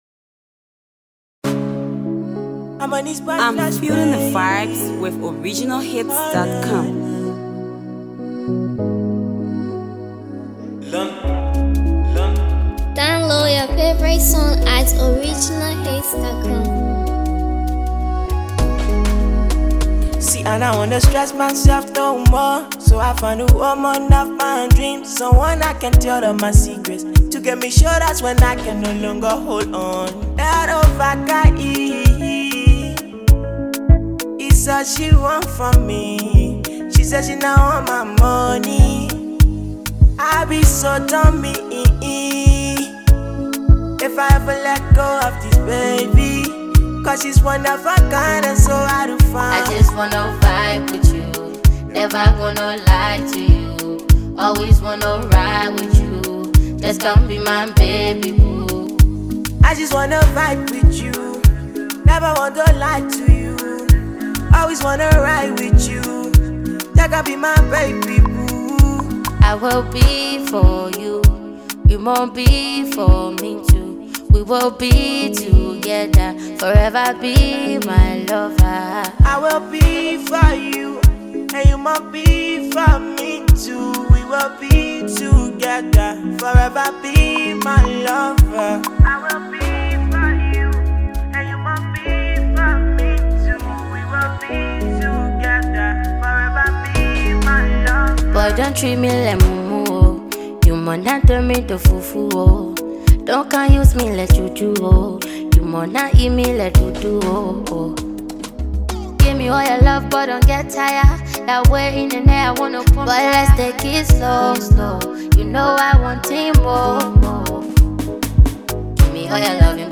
It’s a melody masterpiece jam that will suit your playlist.